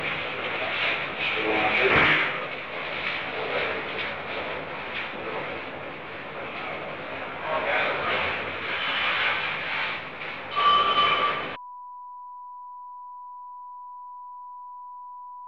Conversation: 482-030
Recording Device: Oval Office
Location: Oval Office